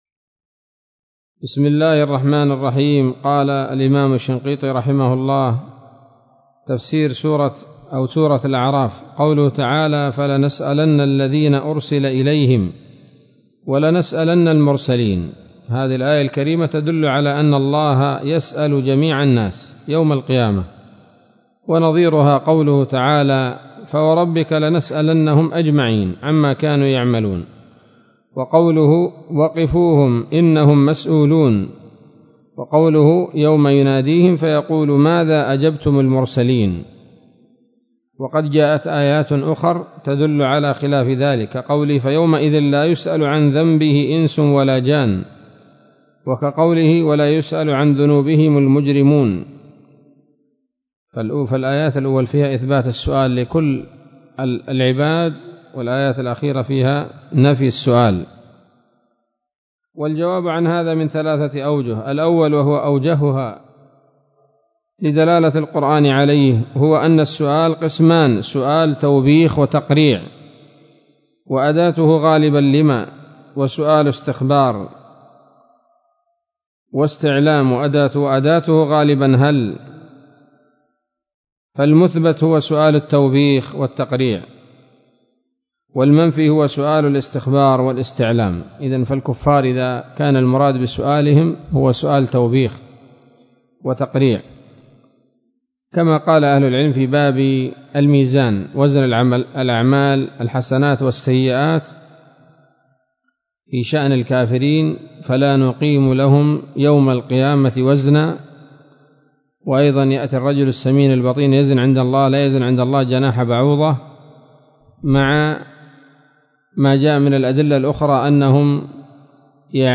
الدرس الرابع والأربعون من دفع إيهام الاضطراب عن آيات الكتاب